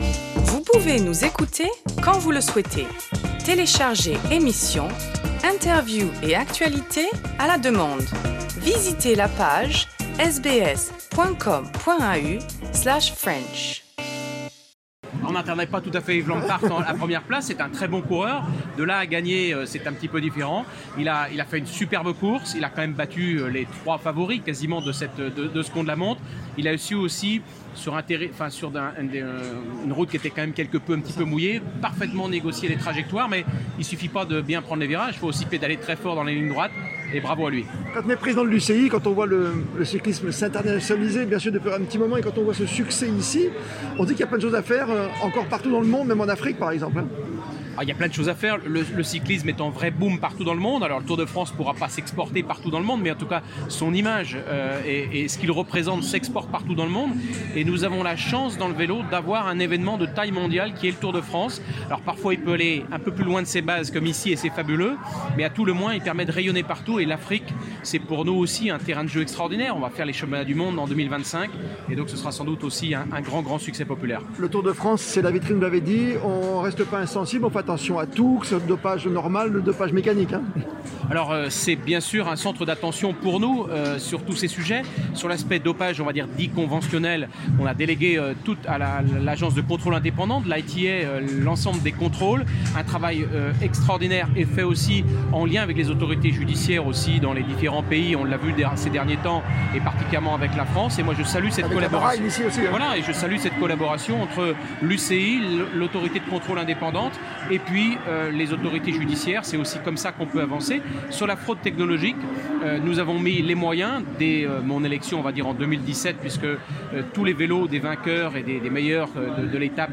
Le président de l'Union cycliste internationale (UCI), David Lappartient, s'est confié au sujet de cette édition 2022.